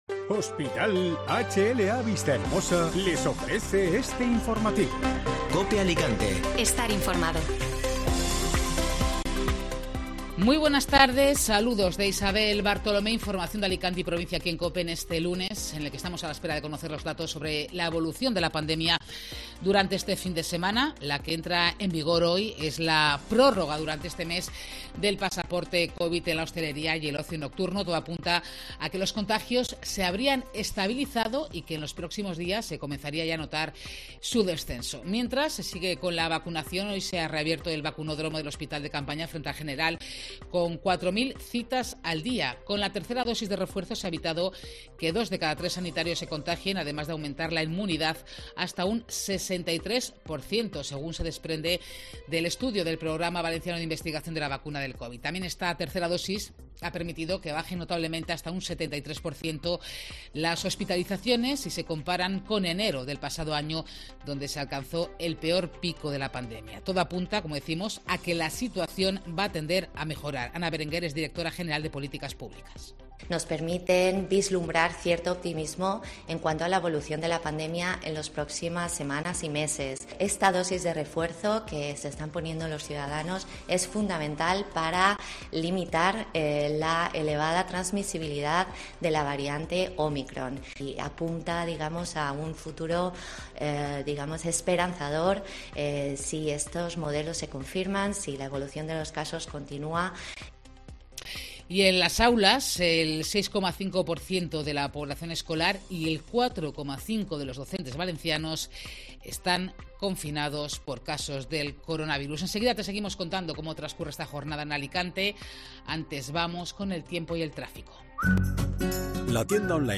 Informativo Mediodía COPE Alicante (Lunes 31 de enero)